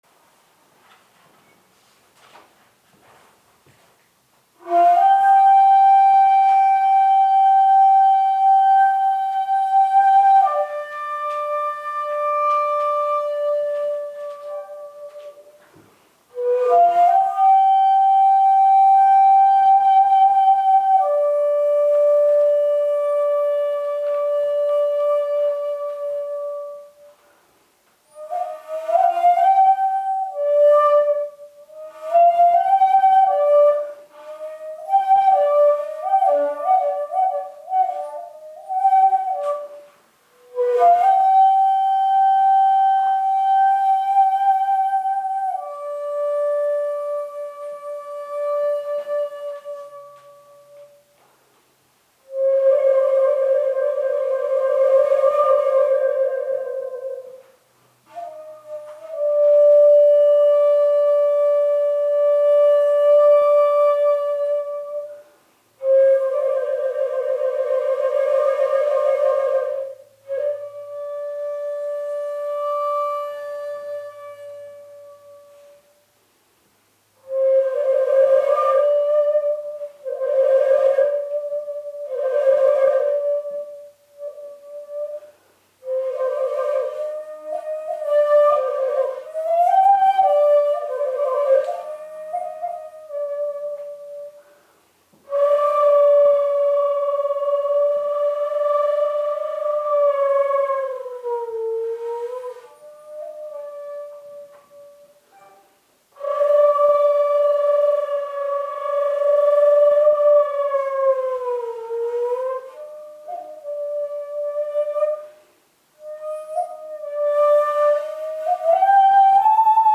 一休さんと尺八コンサート
プログラム　第1部　尺八古典本曲
琴古流本曲